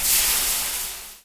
fizz.ogg